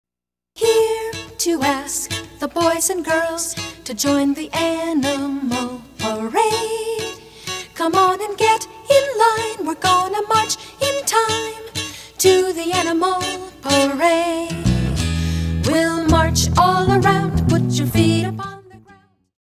children's music
Original activity songs to teach motor skills and concepts!